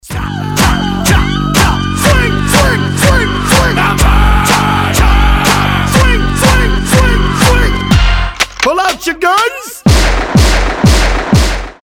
• Качество: 320, Stereo
громкие
Хип-хоп
выстрел
страшные
крики
Орётся, конечно, now murder, но очень похоже на Тамару)